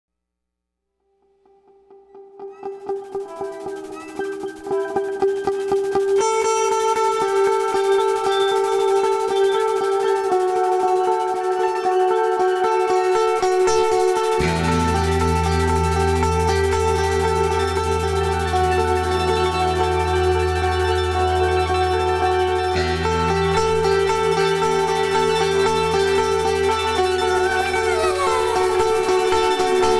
Adventurous Electronic Excursions
Voice with Live Processing
Guitar and SuperCollider